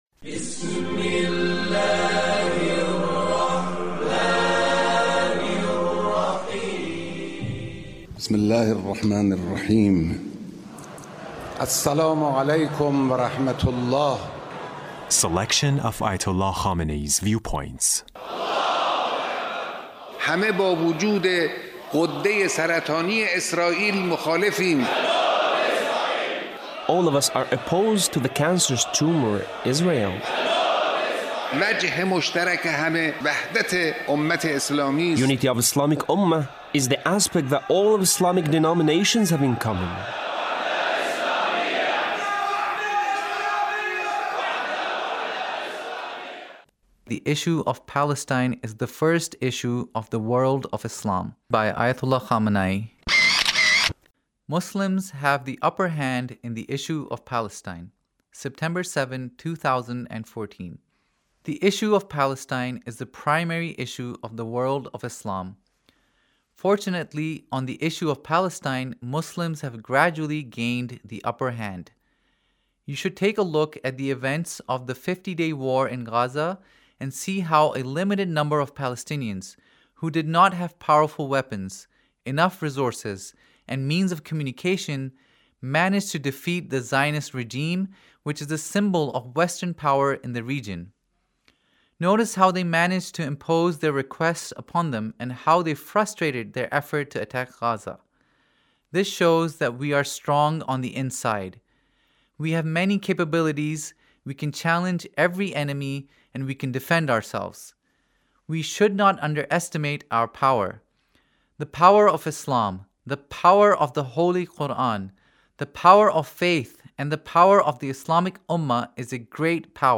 Leader's Speech (1870)